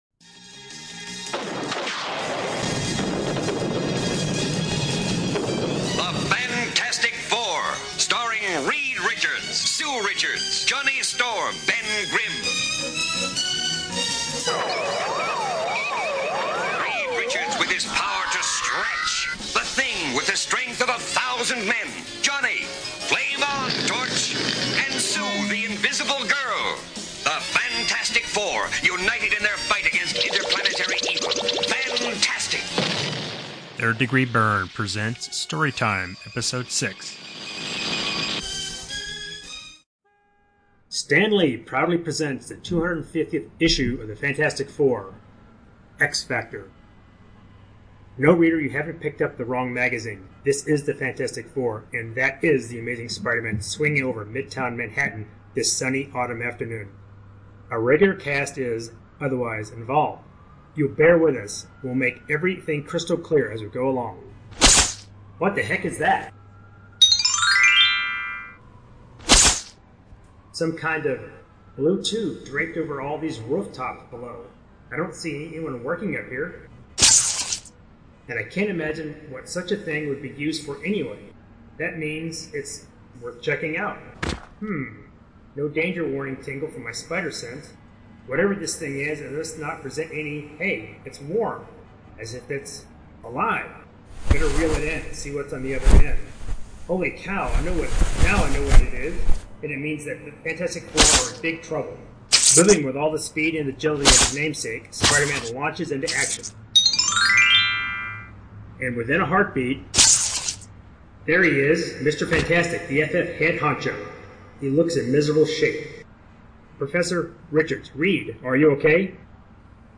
3rd Degree Byrne presents a special Fantastic Four tie-in edition of Story Time. The team has gathered an all star cast of voice actors to present a dramatic reading of Fantastic Four #250. Remember if you are reading along to turn the page when you hear the tone.